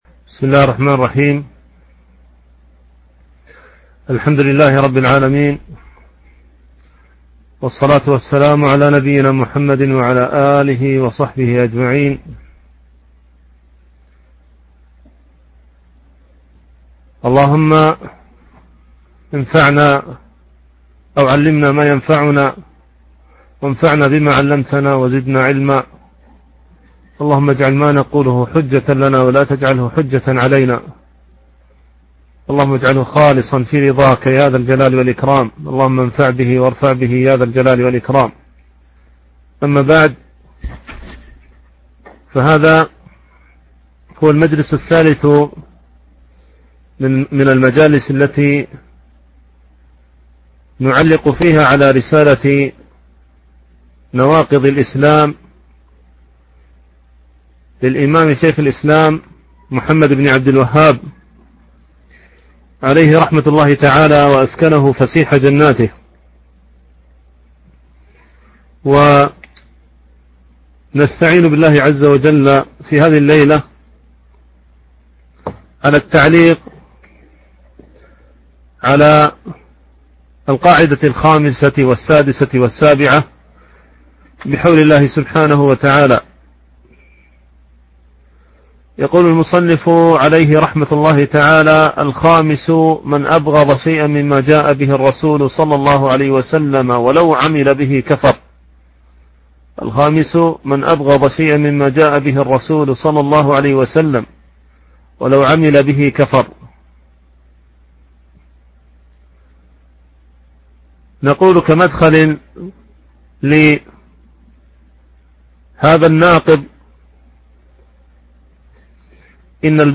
شرح نواقض الإسلام ـ الدرس الثالث
التنسيق: MP3 Mono 22kHz 32Kbps (CBR)